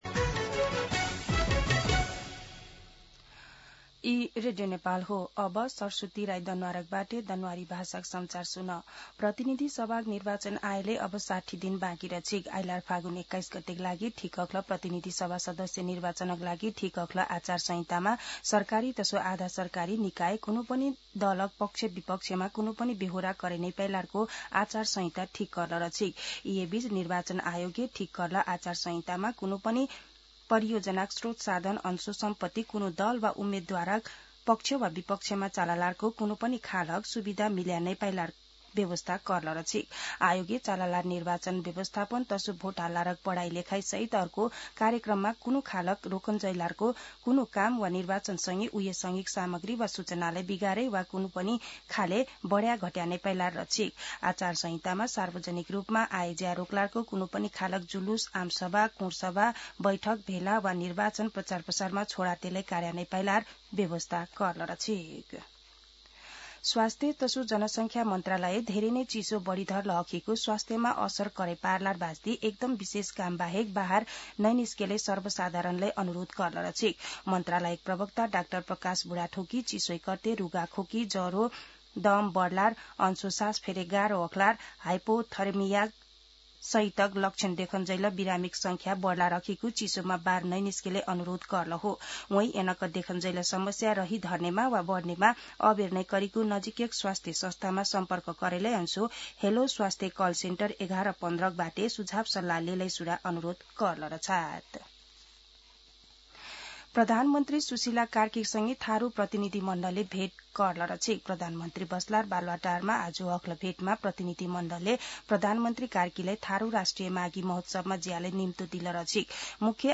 दनुवार भाषामा समाचार : २० पुष , २०८२
Danuwar-News-20.mp3